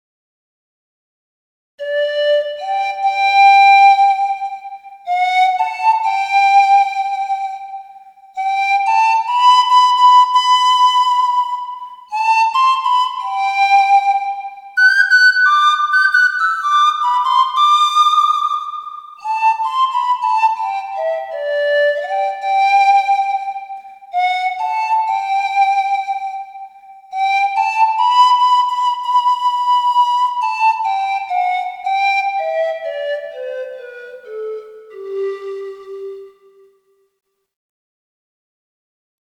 Пан-флейта Gibonus FPS-Alto
Пан-флейта Gibonus FPS-Alto Тональность: G
Диапазон - три октавы (G1-G4), строй диатонический.